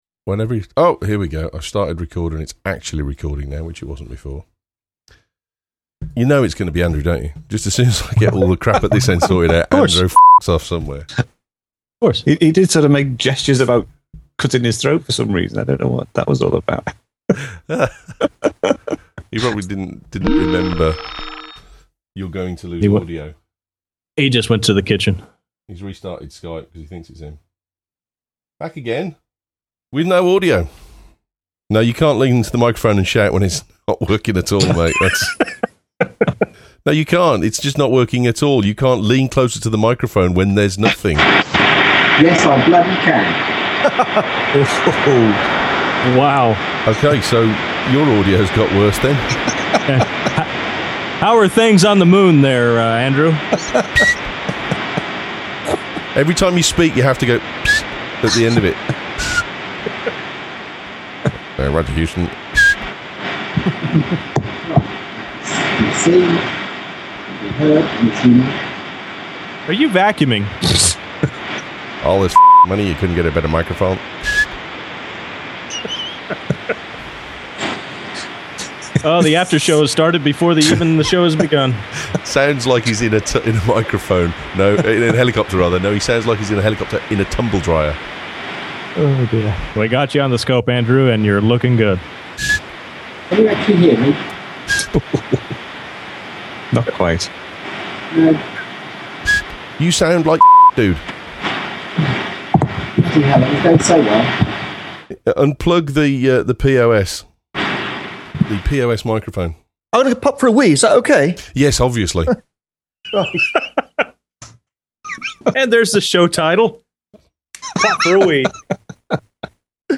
So with all the audio issues we had on Friday, its a wonder we got anything out at all, but we managed